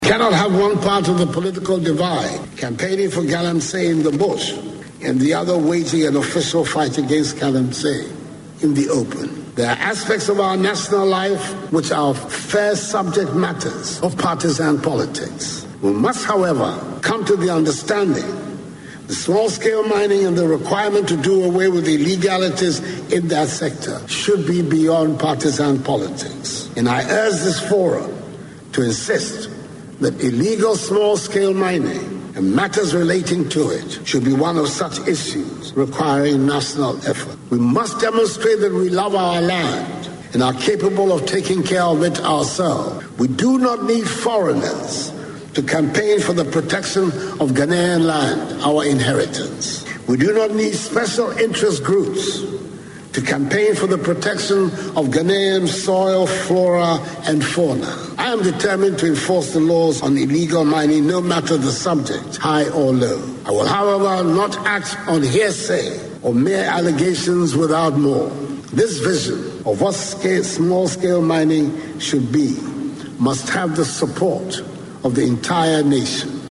Speaking at the open ceremony of the dialogue, he said his government will not relent to deal with people engage in illegal mining and ensure that law has been set to deal with.